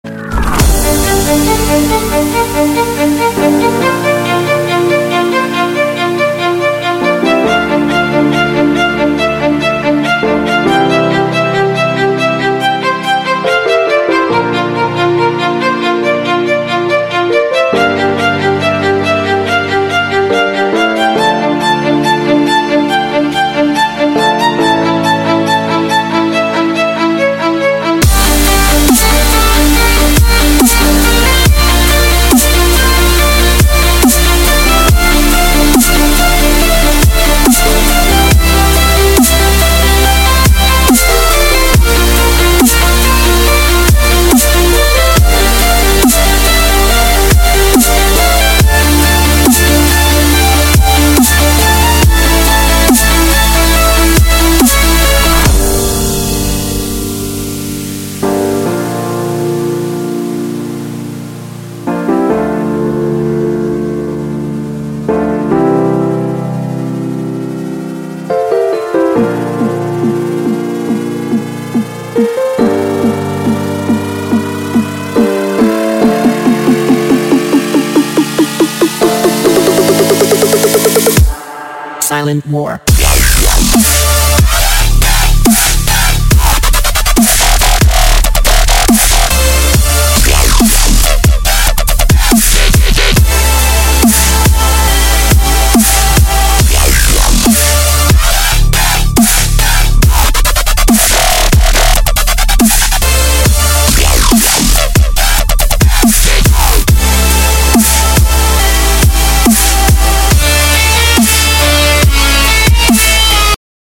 • Качество: 128, Stereo
без слов
скрипка
инструментальные
классика
Классика и Дабстеп.